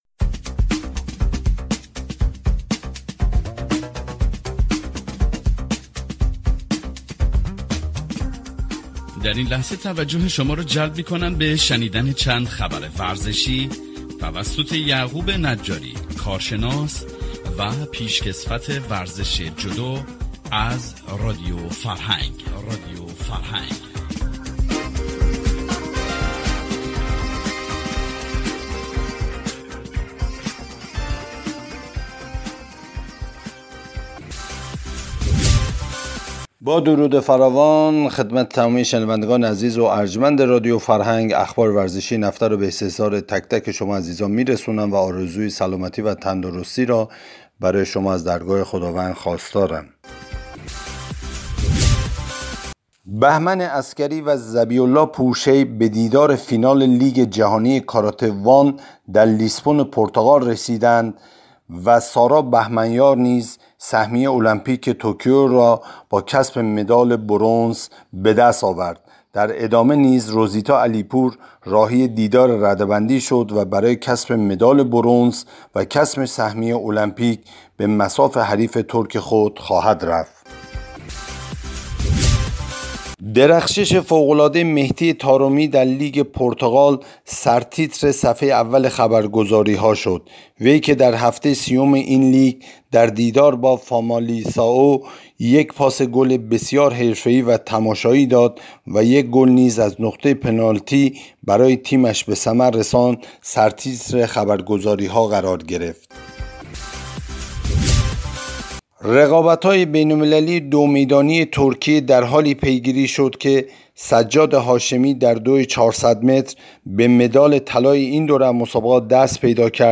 اخبار ورزشى